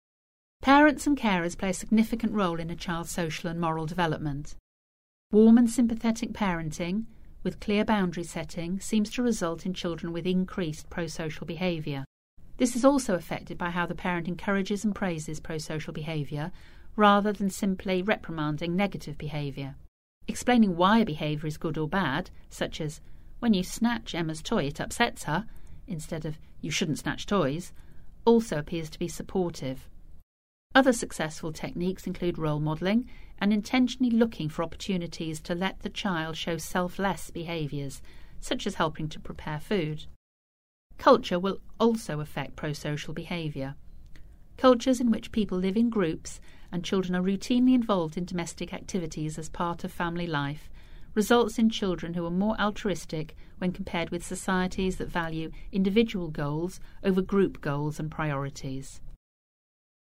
Narration audio